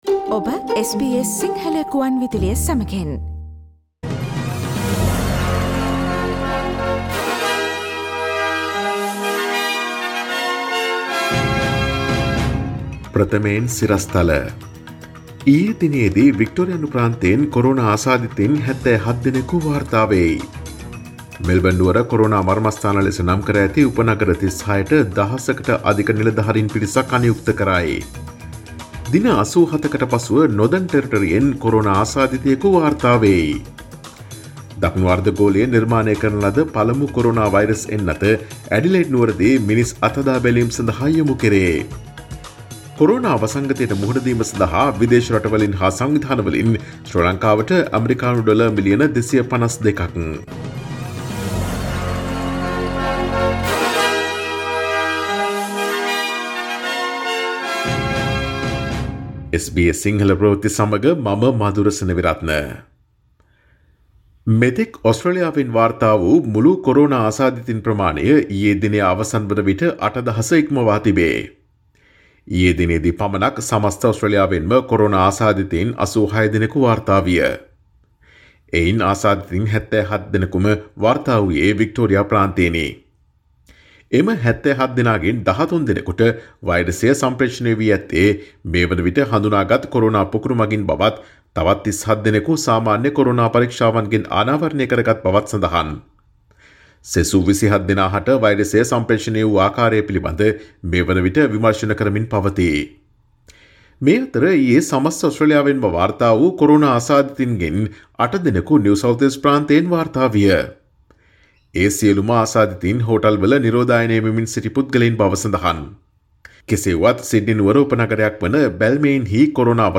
Daily News bulletin of SBS Sinhala Service: Friday 03 July 2020